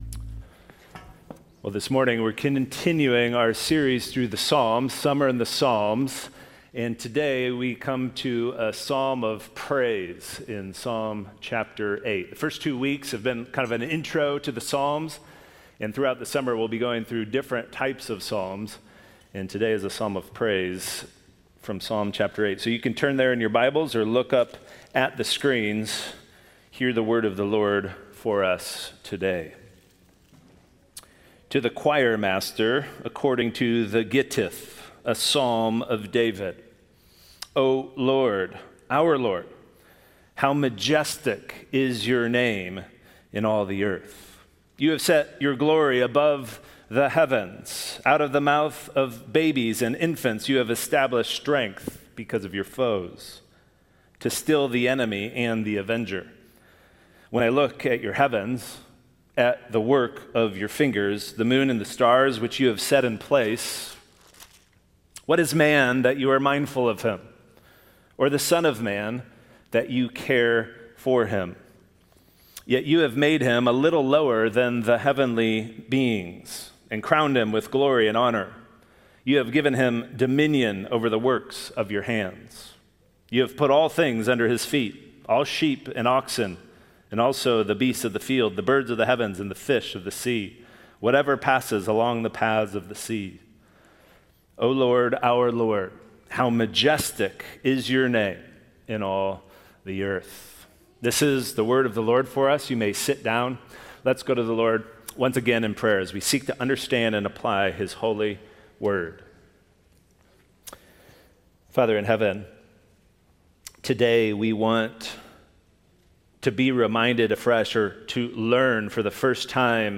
The weekly sermon from Hope Fellowship Church
Sermons